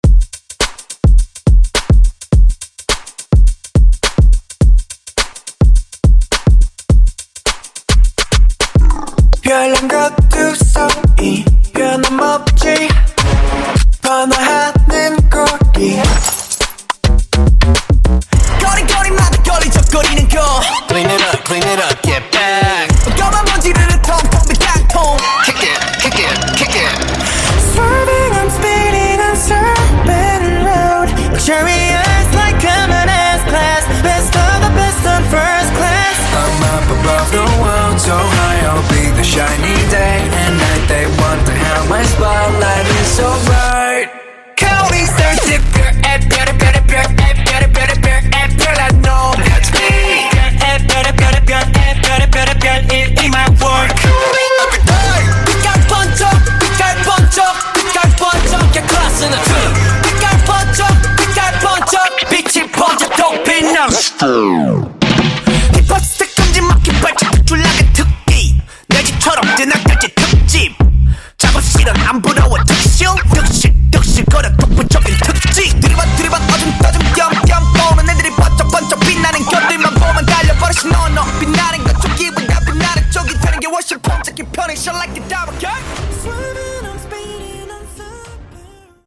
Genres: GERMAN MUSIC , RE-DRUM , TRAP
Dirty BPM: 136 Time